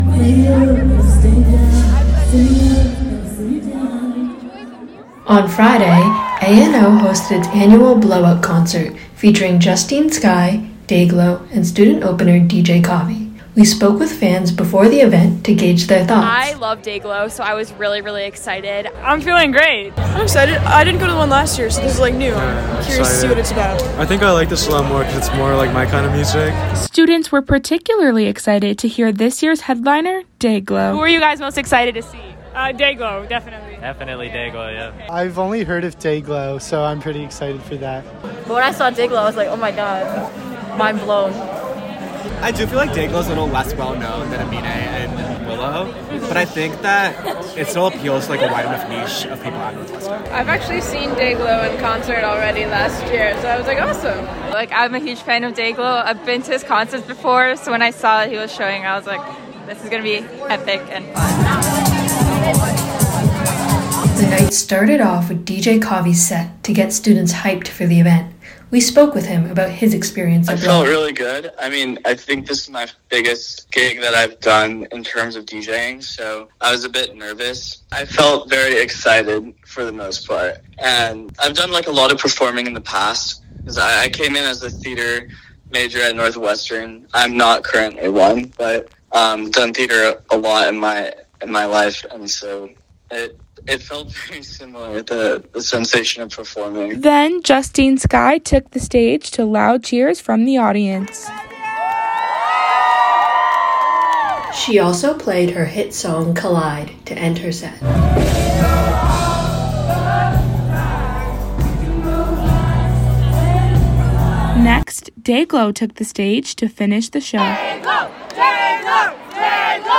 Writers’ note: All concert audio was obtained from student audience members.